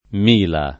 m&la] (ant. o poet. milia) num.